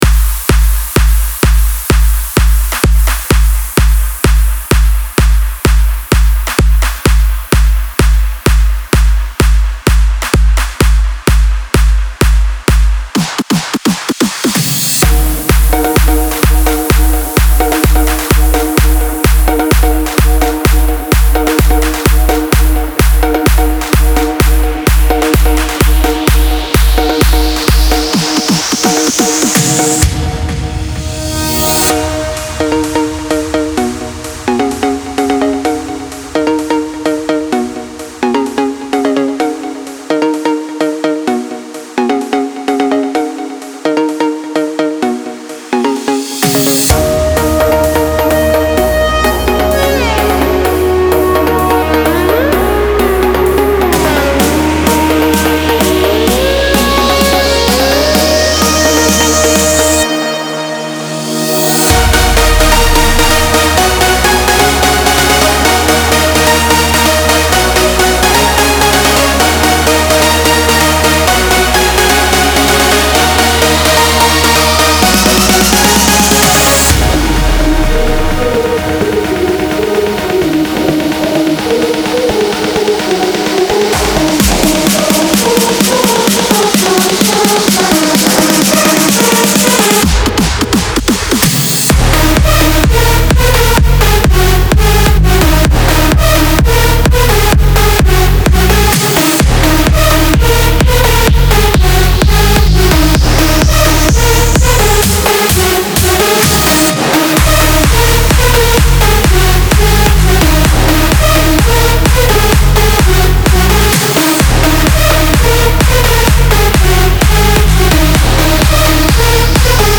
Стиль: Dutch House